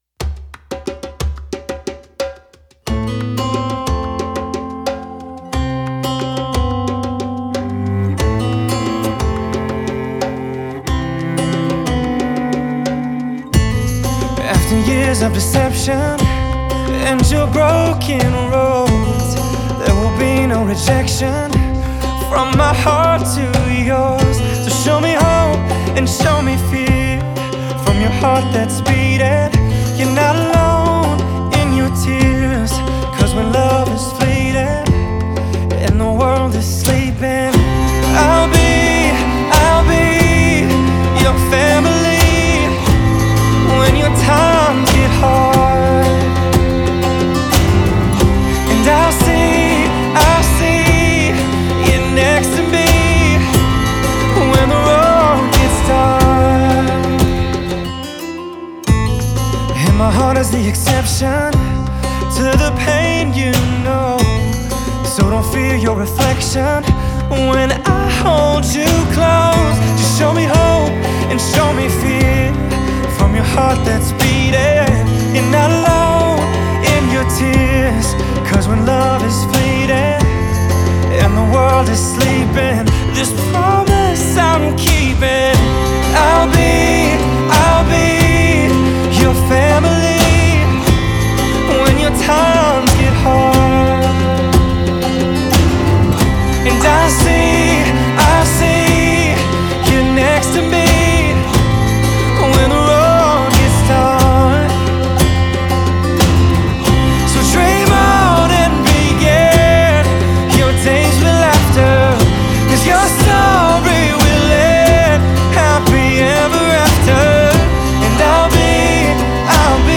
Genre: Pop-Rock, Soft-Rock, Acoustic.